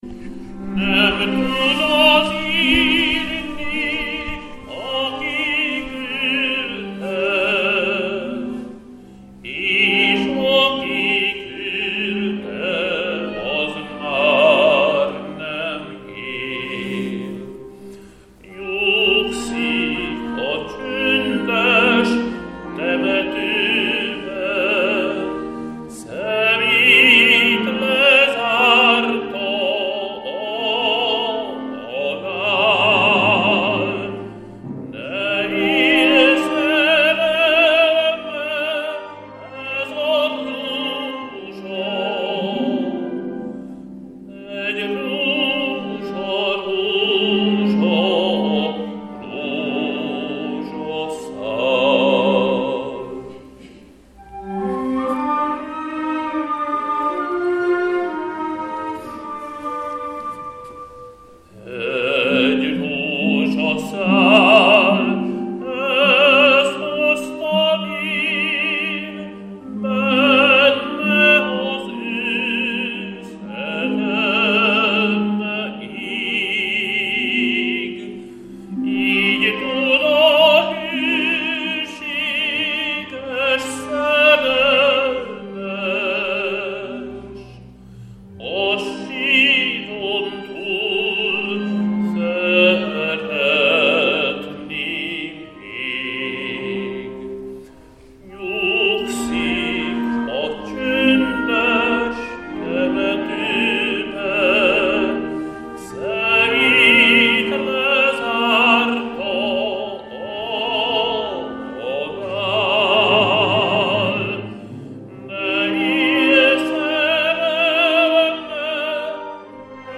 Adventi koncert templomunkban